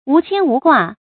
注音：ㄨˊ ㄑㄧㄢ ㄨˊ ㄍㄨㄚˋ
無牽無掛的讀法